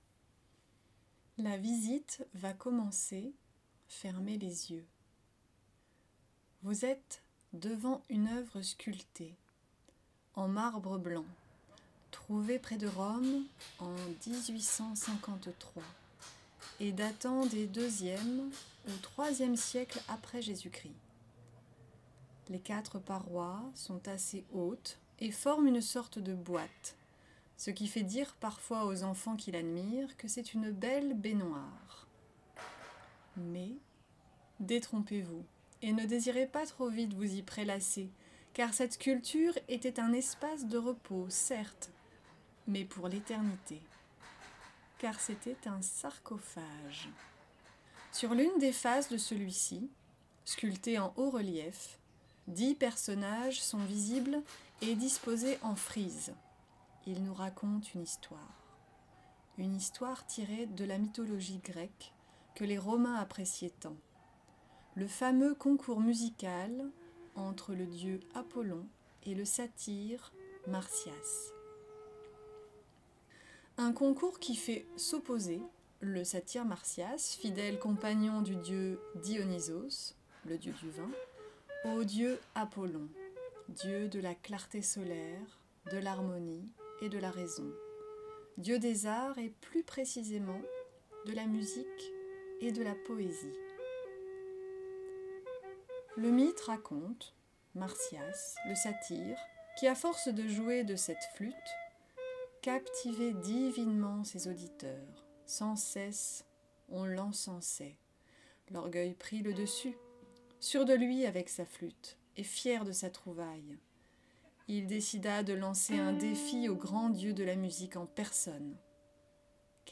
Louvre-Lens > Mon Louvre-Lens > Lectures audiodescriptives > Le sarcophage d’Apollon et Marsyas